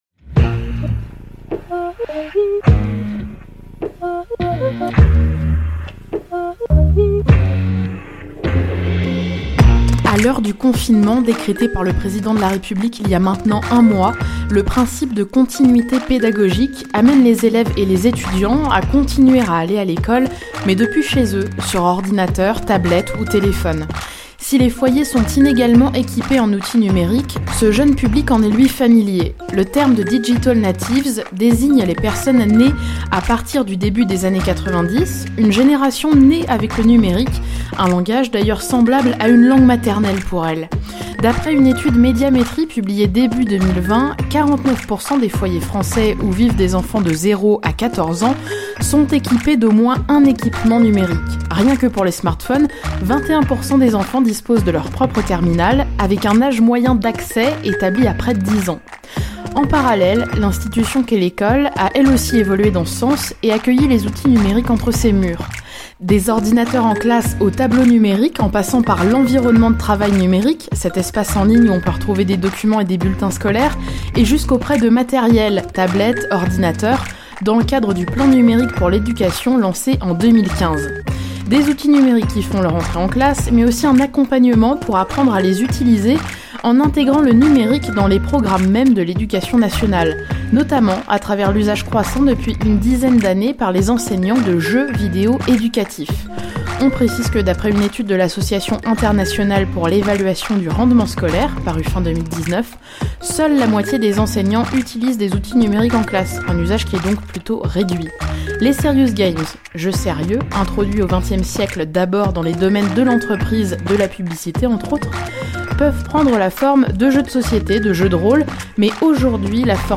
elle répond aux questions